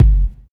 62 KICK 4.wav